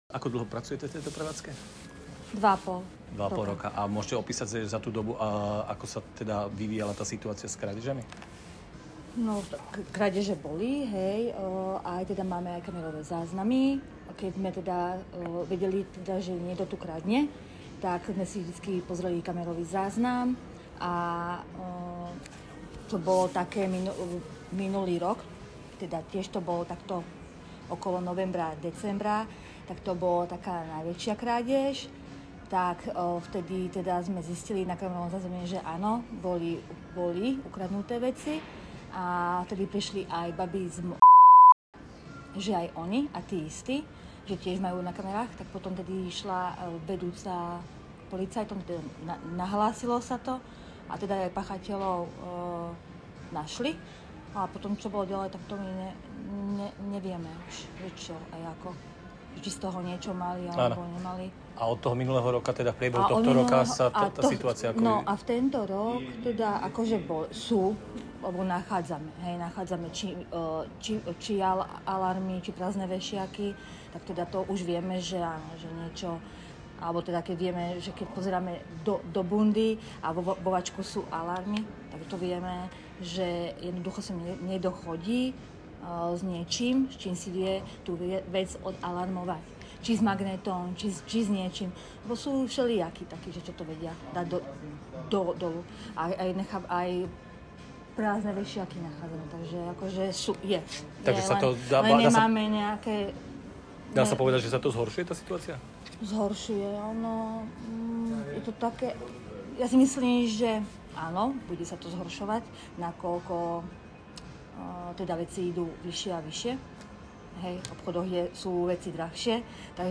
Rozhovor s predavačkou o krádežiach v obchodoch.